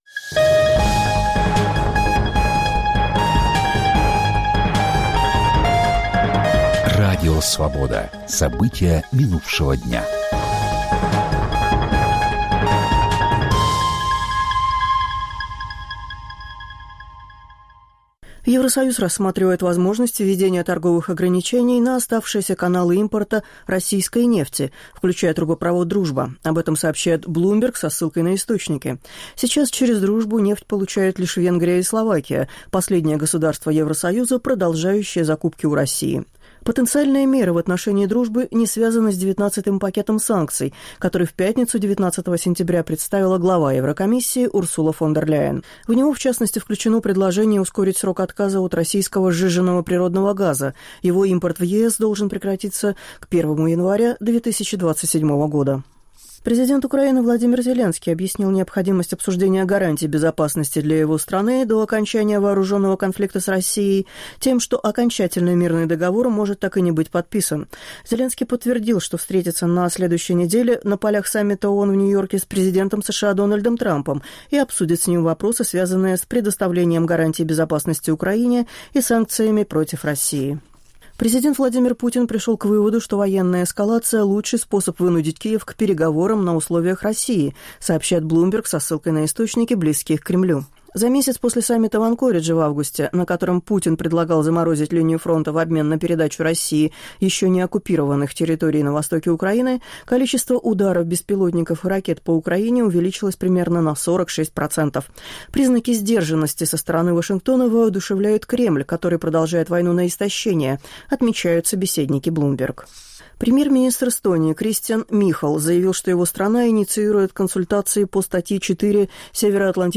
Аудионовости
Новости